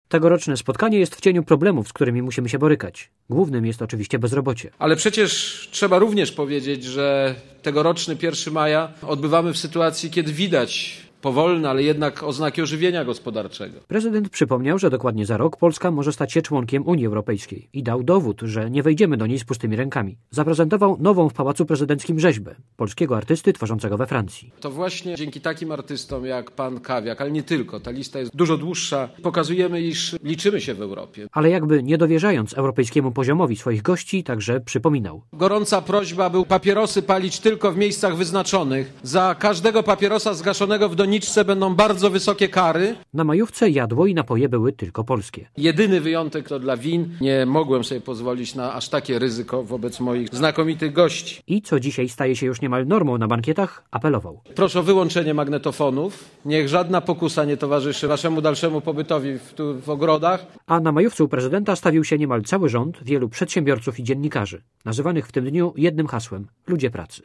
Komentarz audio (525Kb)